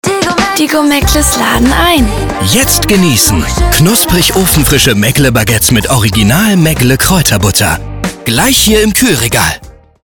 sehr variabel, dunkel, sonor, souverän, markant
Mittel minus (25-45)
Norddeutsch
Commercial (Werbung)